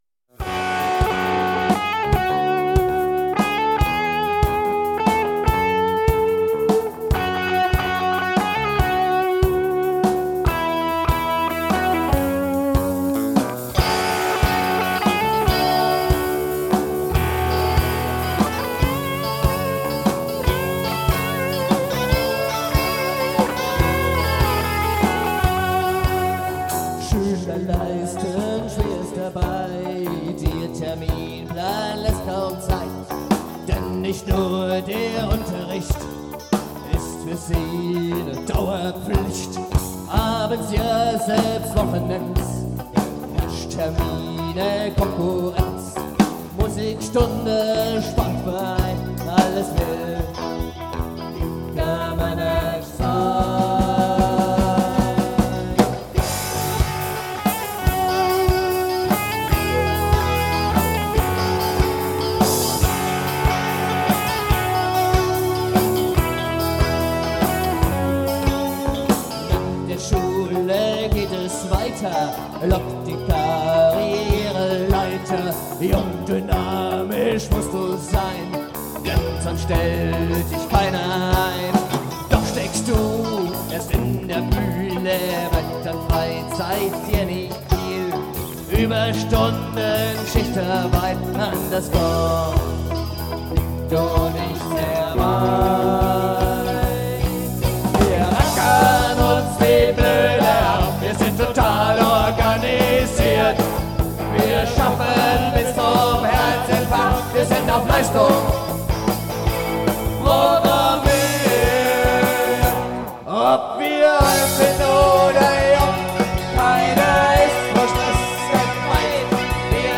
Audio (live; 5:17)Herunterladen